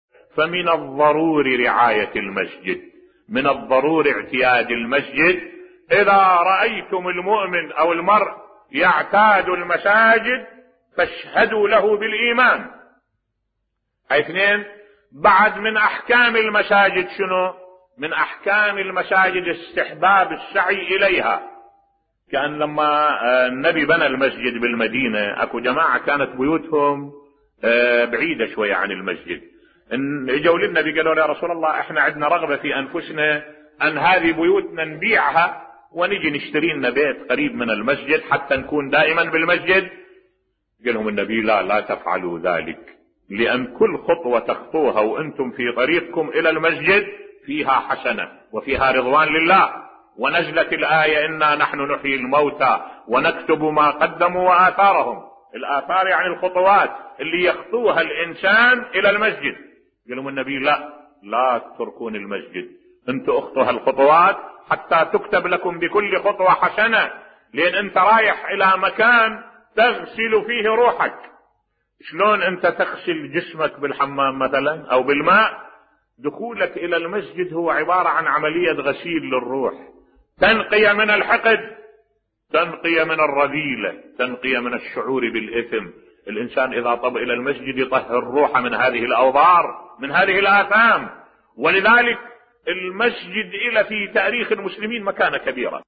ملف صوتی تعليل جميل لاستحبابية المشي إلى المساجد بصوت الشيخ الدكتور أحمد الوائلي